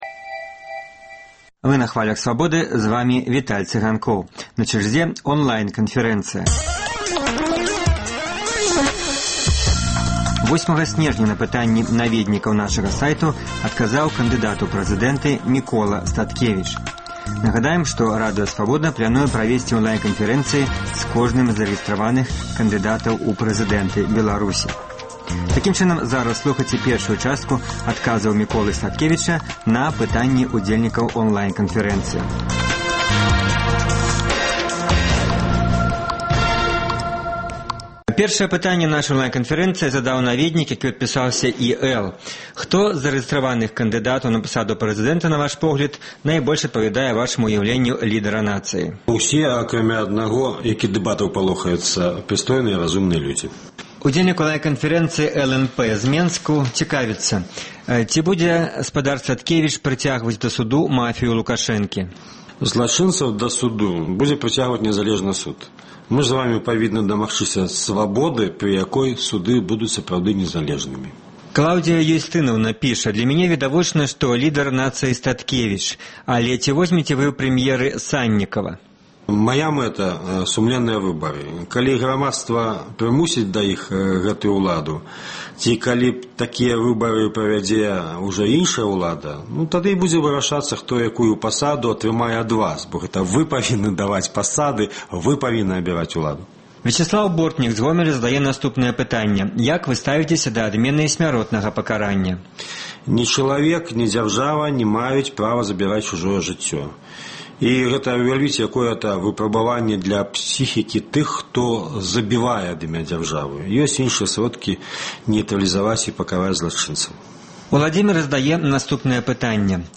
Онлайн-канфэрэнцыя
На пытаньні наведнікаў нашага сайту адказаў кандыдат у прэзыдэнты Яраслаў Раманчук.